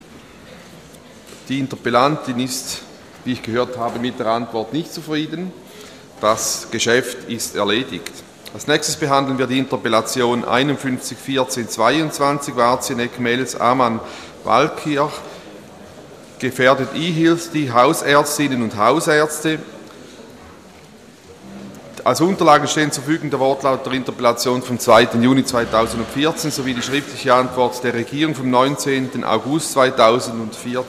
16.9.2014Wortmeldung
Ratsvizepräsident:
Session des Kantonsrates vom 15. und 16. September 2014